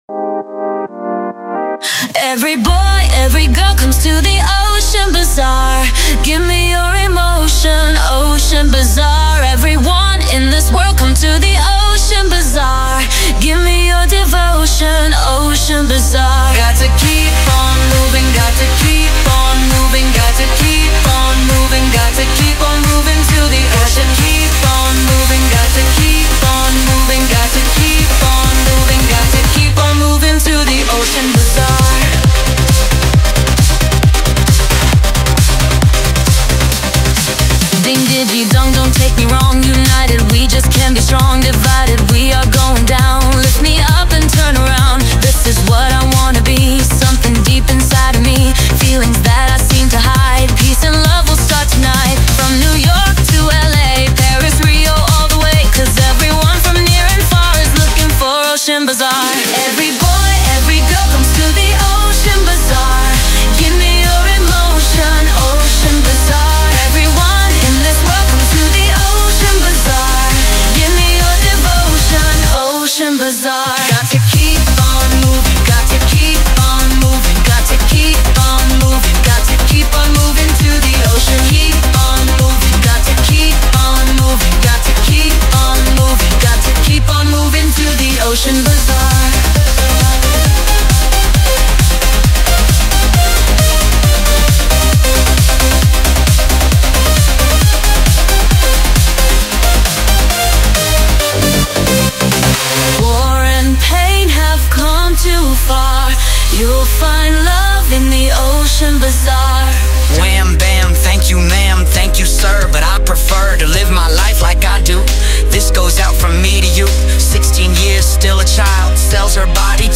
ремиксы же уже на современной основе тоже не плохи...